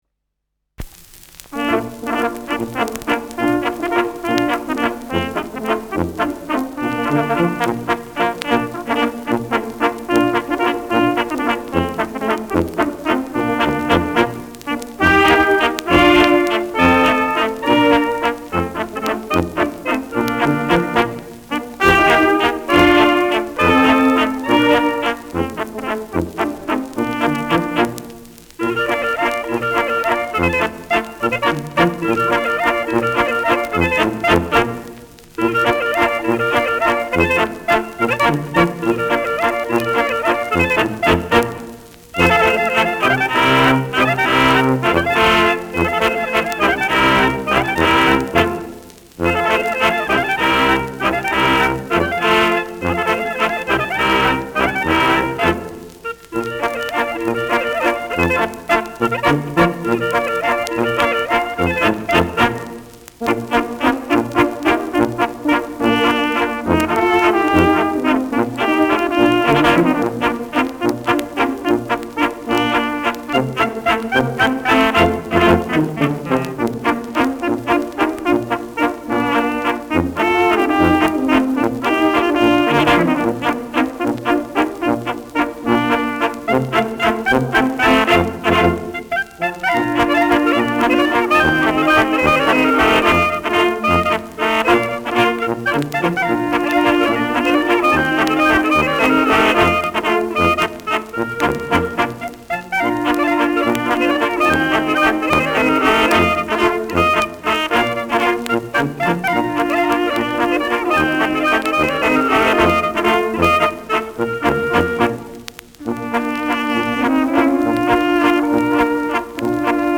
Schellackplatte
leichtes Knistern : leichtes Rauschen